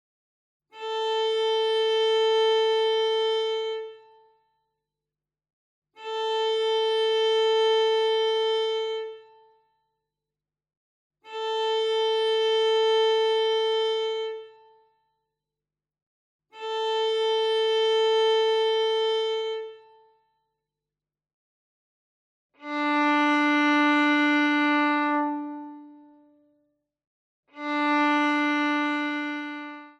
Stimmtöne
VHR 3812_Stimmtöne.mp3